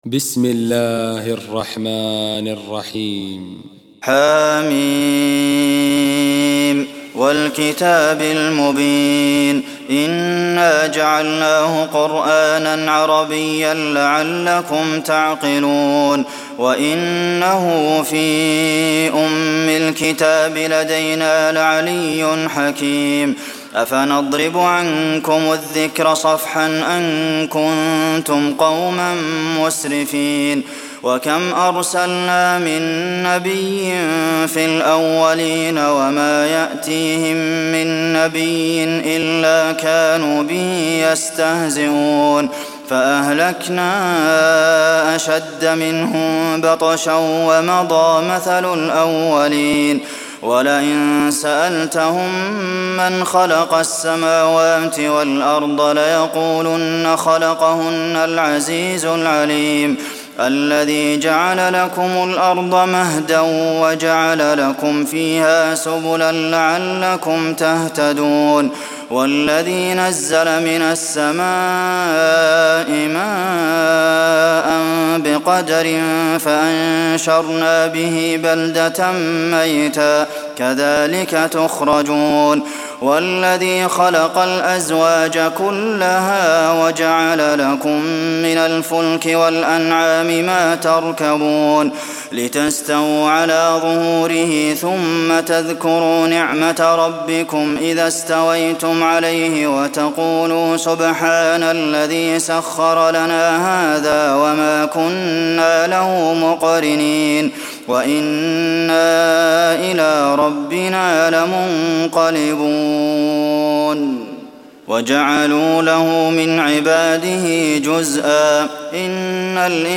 تراويح ليلة 24 رمضان 1423هـ من سور الزخرف (1-89) والدخان (1-16) Taraweeh 24 st night Ramadan 1423H from Surah Az-Zukhruf and Ad-Dukhaan > تراويح الحرم النبوي عام 1423 🕌 > التراويح - تلاوات الحرمين